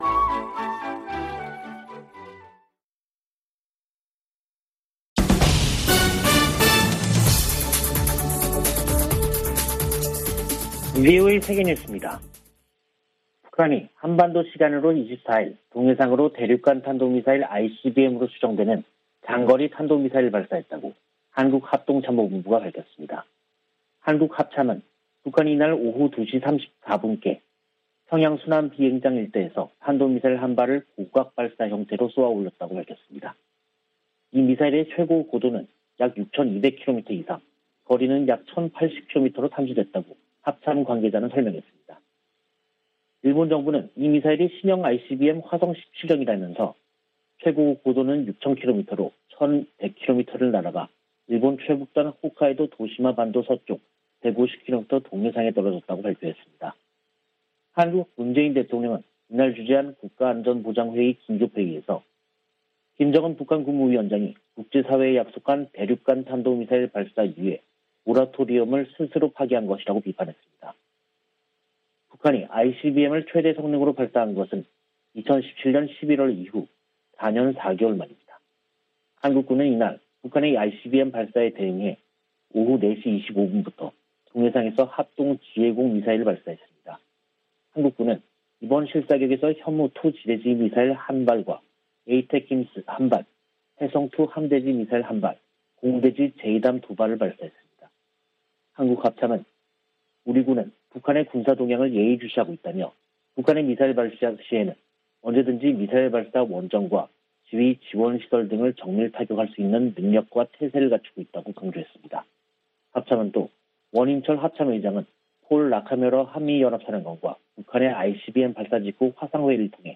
VOA 한국어 간판 뉴스 프로그램 '뉴스 투데이', 2022년 3월 24일 3부 방송입니다. 북한이 24일 대륙간탄도미사일(ICBM)으로 추정되는 미사일을 발사했습니다.